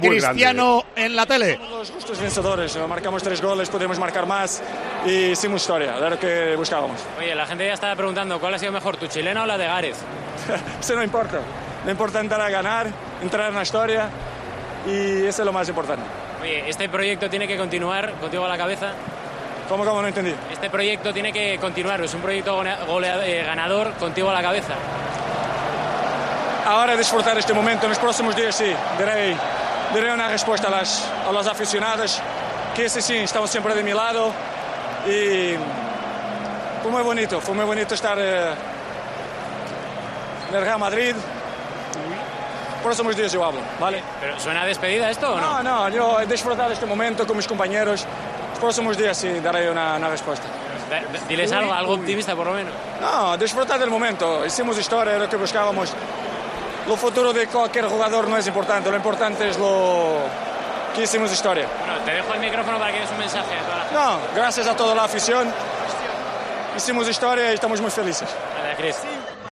El jugador portugués ha dejado dudas sobre su futuro en los micrófonos de BeIN al término de la final de Champions: "No importa que chilena es, lo importante es ganar y entrar en la historia.